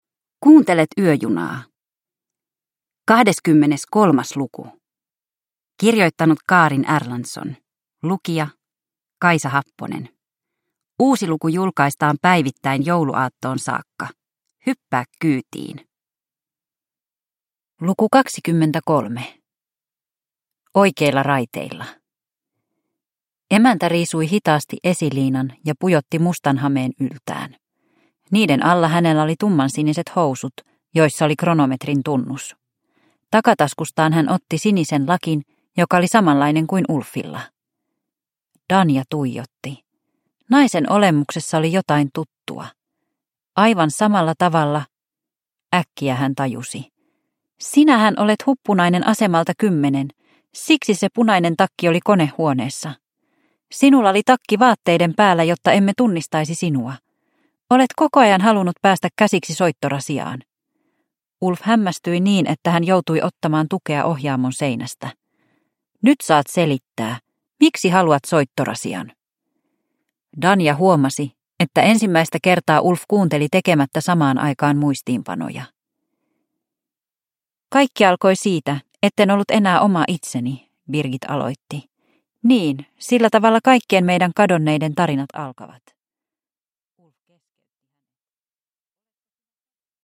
Yöjuna luku 23 – Ljudbok